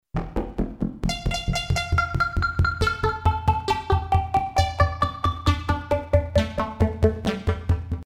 REVIEW "Fun unit with very lo fidelity sounds. Some very good textures out of PCM and FM syntheses."